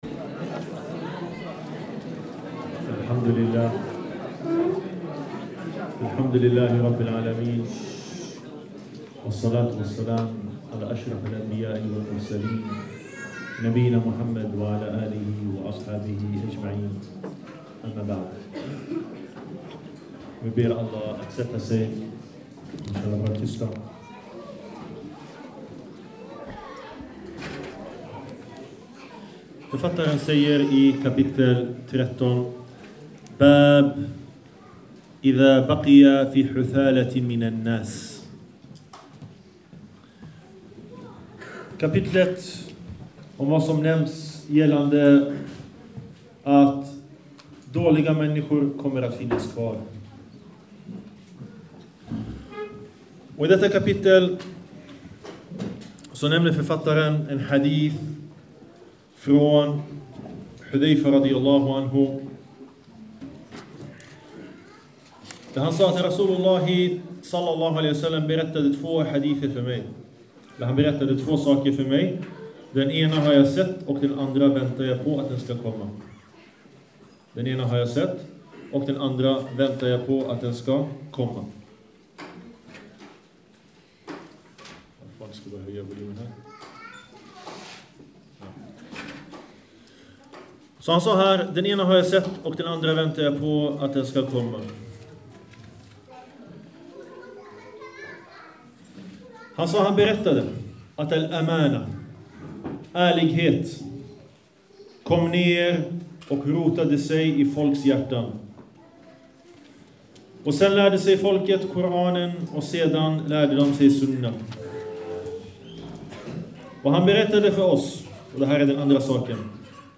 En föreläsning
Imam al-Bukhari konferens december 2014.